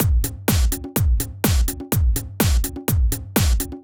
Drumloop 125bpm 05-A.wav